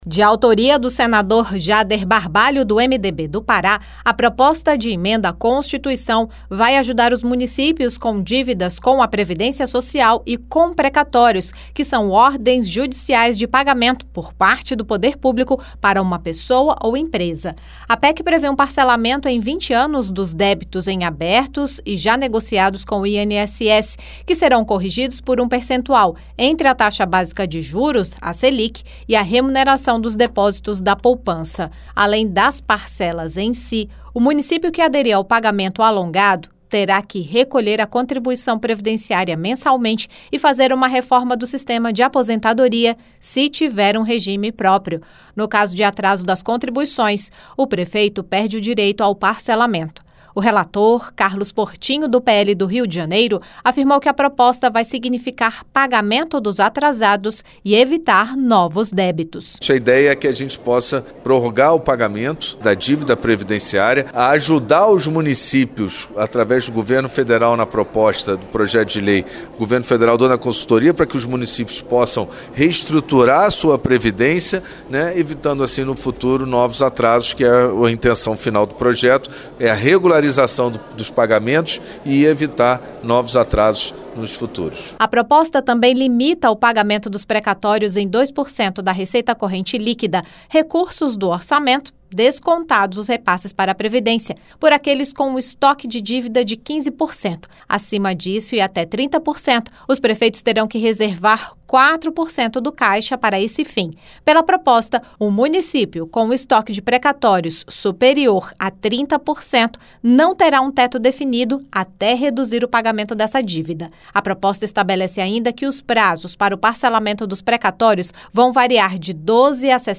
O relator, senador Carlos Protinho (PL-RJ), destacou que a proposta prevê a perda dos benefícios em caso de atraso no acerto das parcelas.